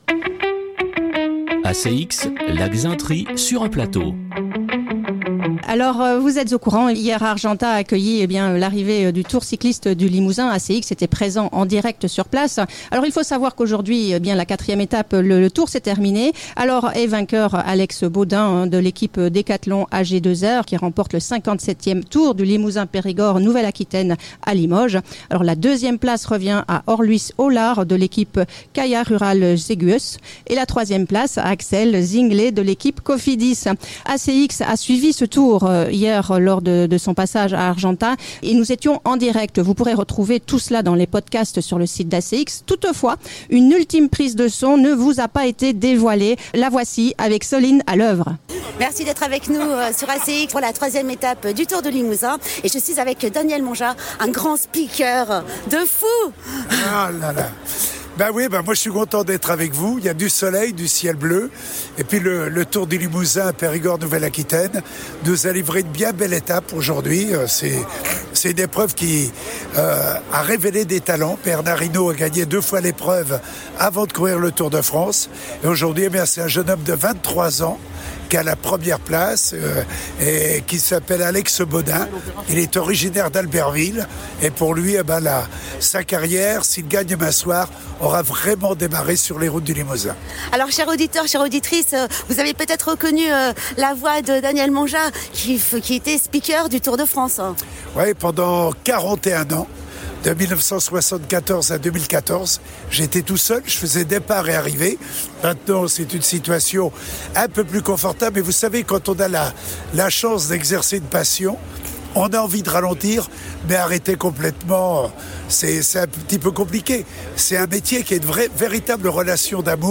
Daniel Mangeas commentateur sportif.